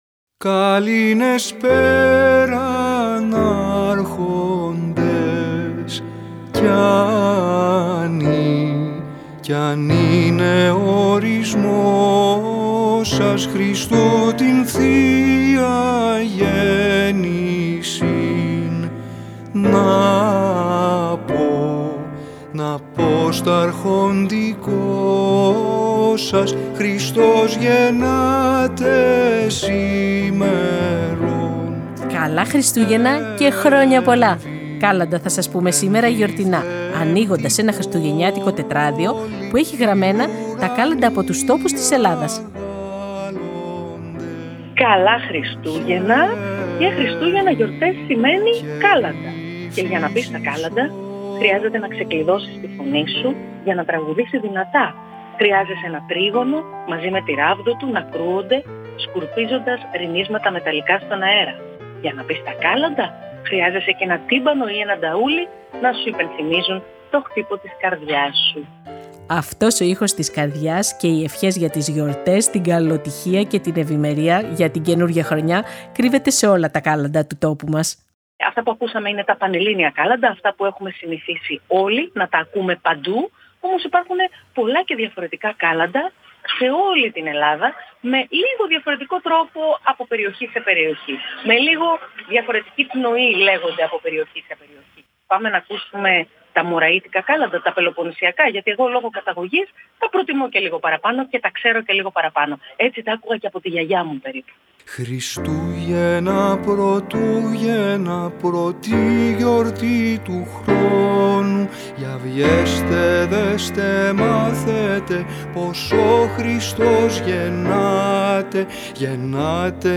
Σήμερα Δευτέρα και Χριστούγεννα η Κόκκινη Σβούρα τραγουδάει τα κάλαντα και σας λέει ό,τι χρειάζεται να ξέρετε για να τα “πείτε καλά”.